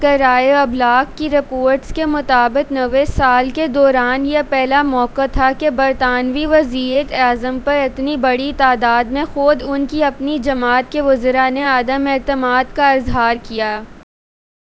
Spoofed_TTS/Speaker_10/259.wav · CSALT/deepfake_detection_dataset_urdu at main
deepfake_detection_dataset_urdu / Spoofed_TTS /Speaker_10 /259.wav